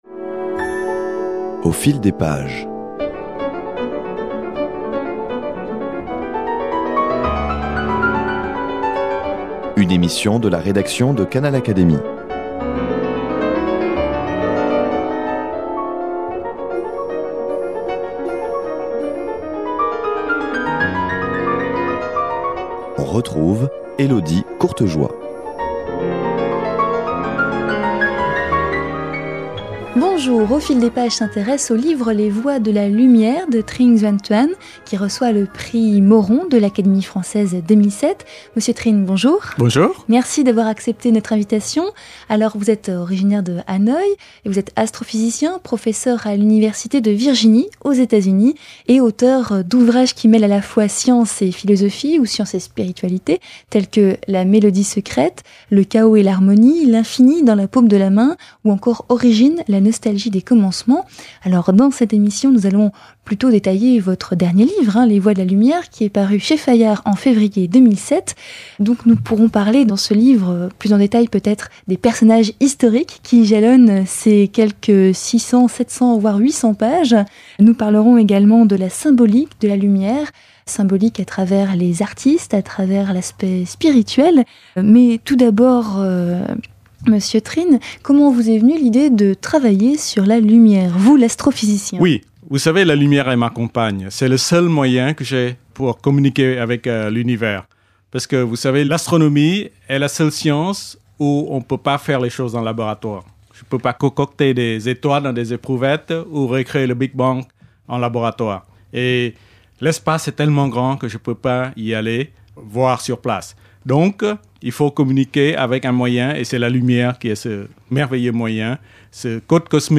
Les voies de la lumière, livre alliant sciences et philosophie, a reçu le prix Moron 2007 de l’Académie française. Trinh Xuan Thuan, astrophysicien, développe dans cette émission deux points de son livre : la lumière d’un point de vue scientifique (à la fois onde et particule) et son symbole en philosophie, en théologie ou encore en musique... Rencontre.